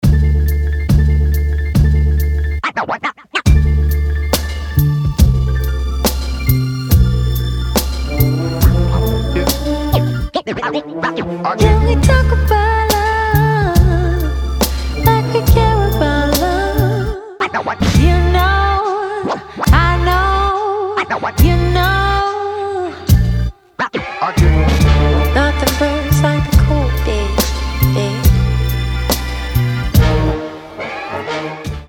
• Качество: 320, Stereo
ритмичные
RnB
красивый женский голос
soul
Rhythm & Blues